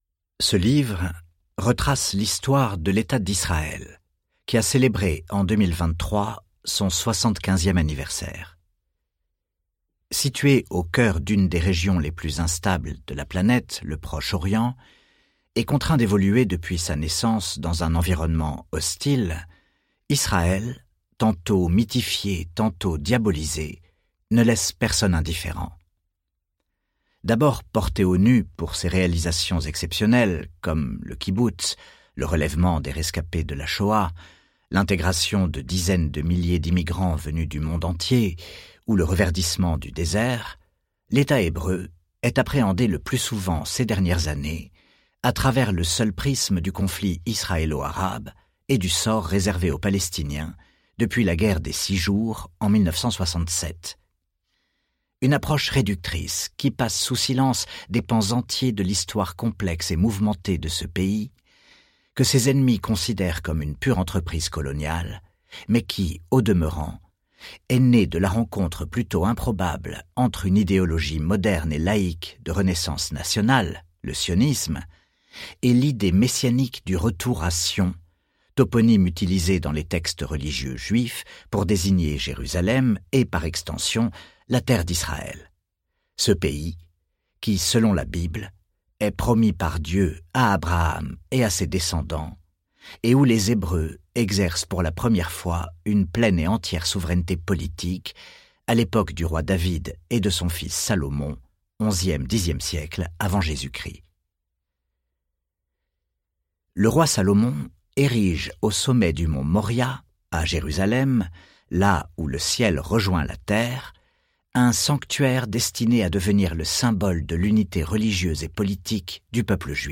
Livre audio Histoire d' Israël Tome 1 de Michel Abitbol | Sixtrid
Texte : Intégral